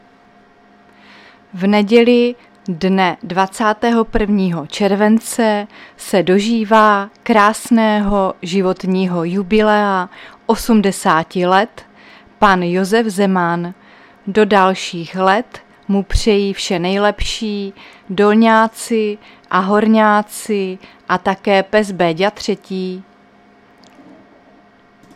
Záznam hlášení místního rozhlasu 19.7.2024
Zařazení: Rozhlas